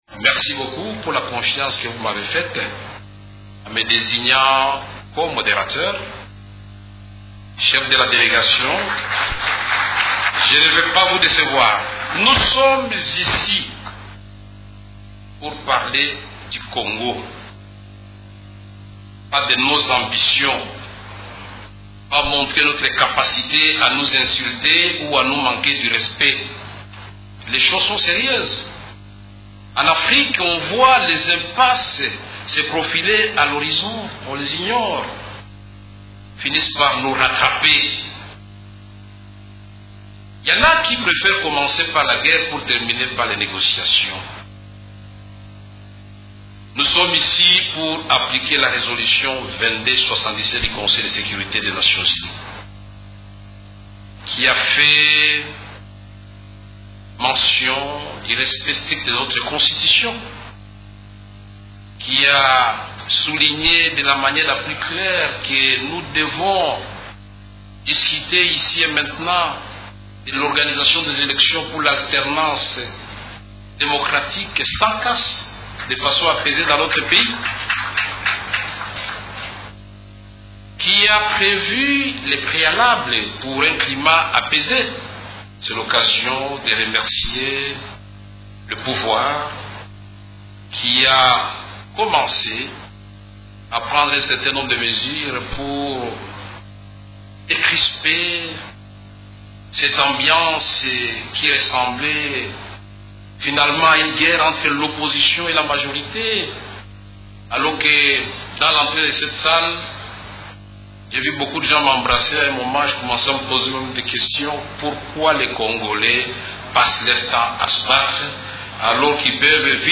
L’opposant Vital Kamerhe a déclaré jeudi 1er septembre à l’ouverture du dialogue politique que lors des travaux préparatoires de ce forum, les délégués de la majorité présidentielle n’avaient pas demandé un troisième mandat pour l’actuel chef de l’Etat Joseph Kabila.
Vous pouvez écouter un extrait des propos de Vital Kamerhe.